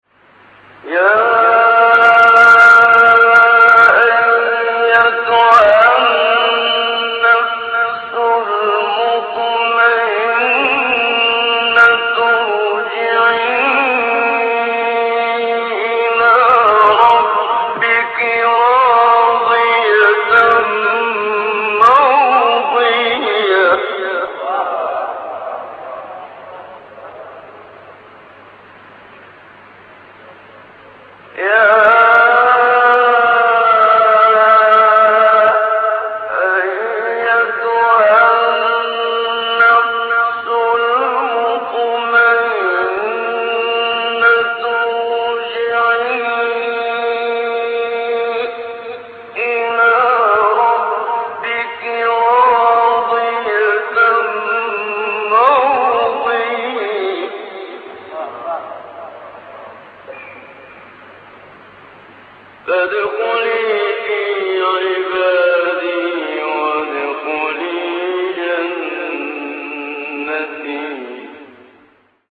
تلاوت استاد محمد صدیق منشاوی سوره مبارکه فجر آیات شریفه ۲۷ تا ۳۰